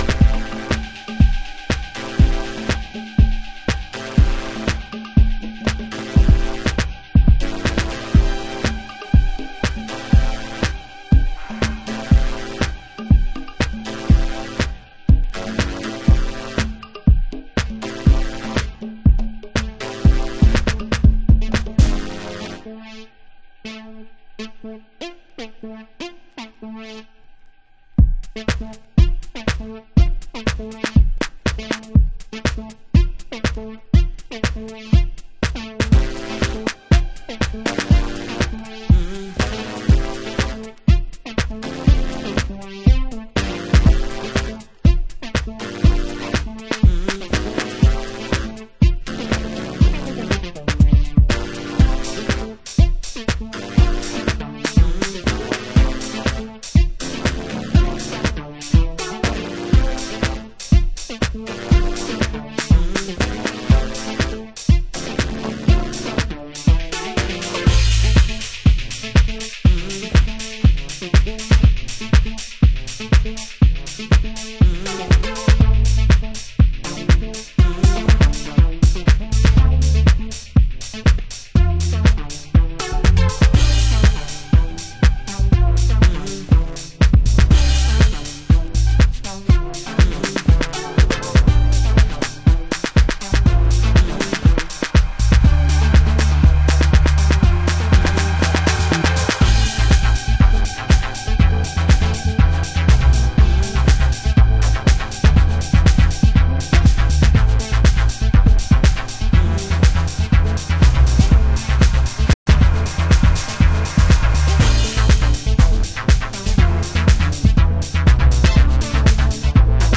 DISCODUB